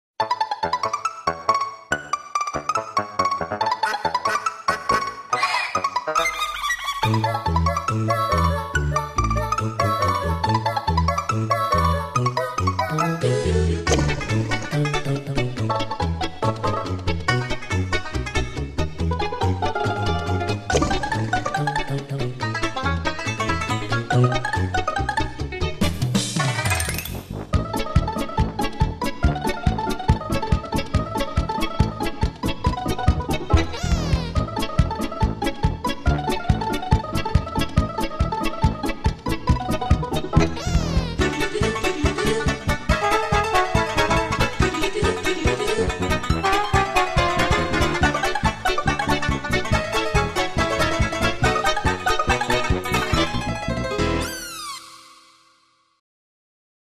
Саундтрек